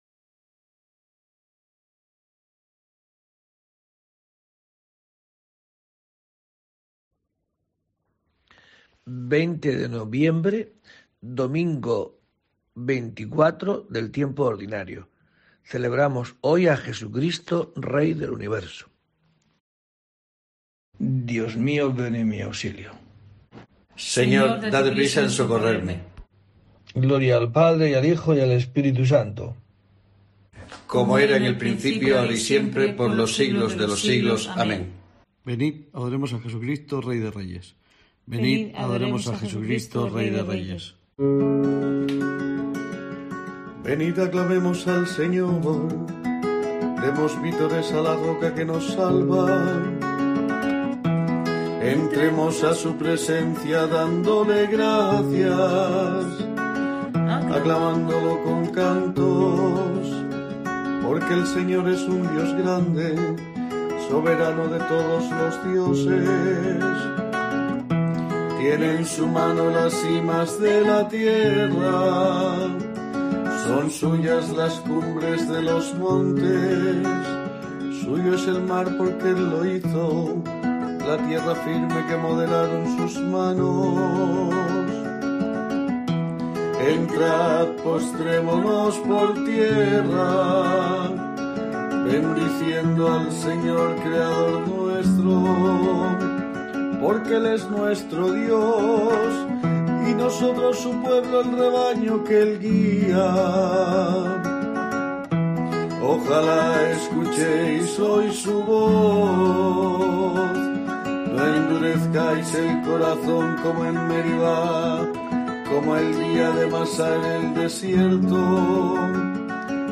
20 de noviembre: COPE te trae el rezo diario de los Laudes para acompañarte